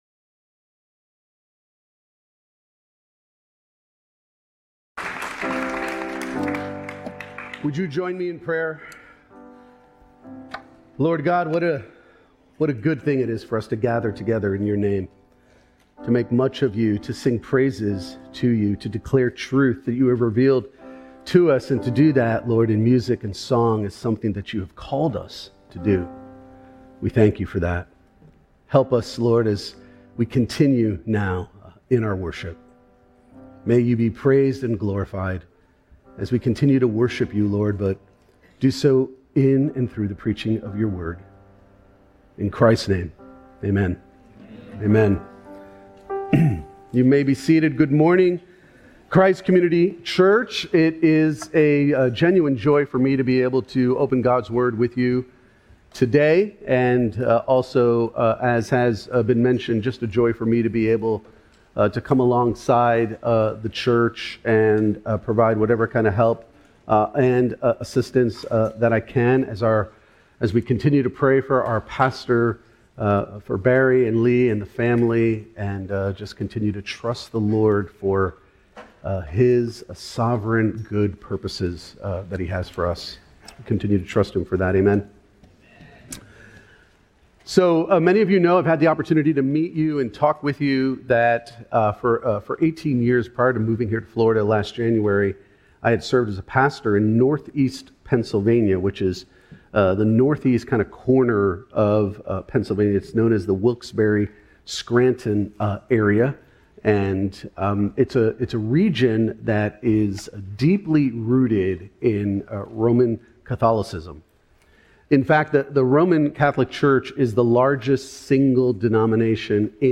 Sermons from Christ Community Church: Daytona Beach, FL